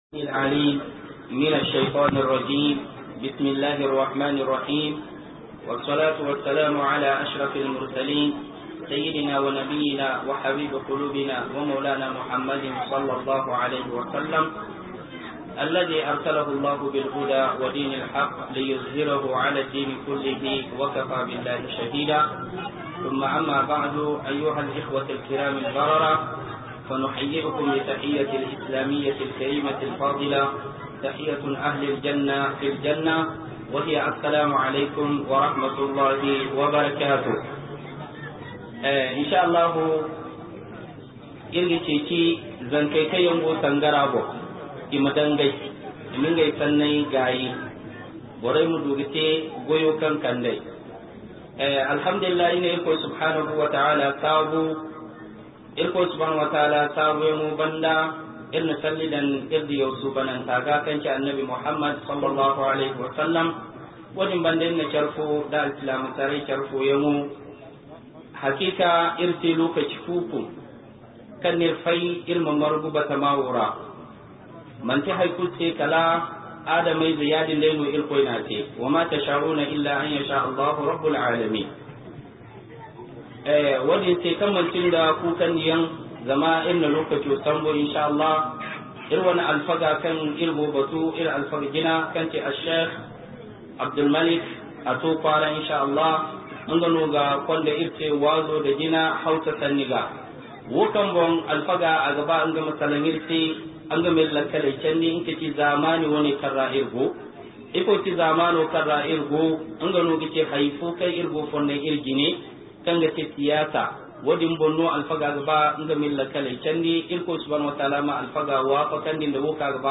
87 - MUHADARA